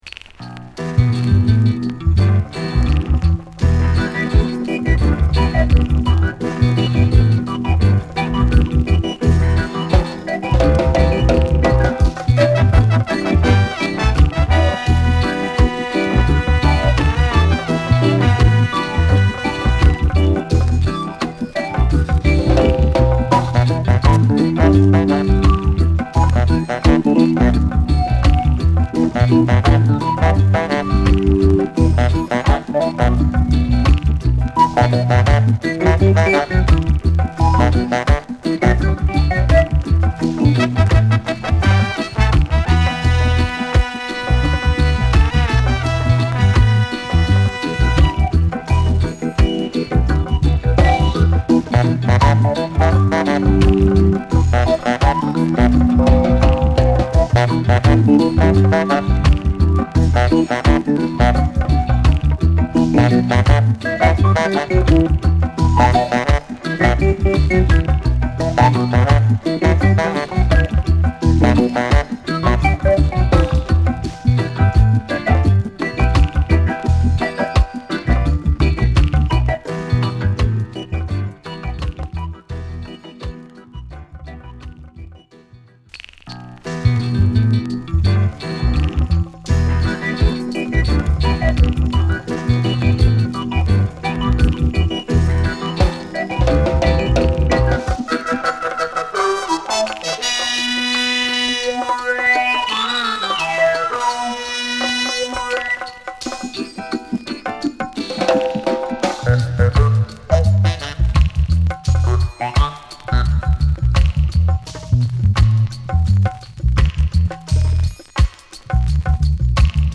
ヘヴィーなリズムにホーンが響くド渋なルーツチューン！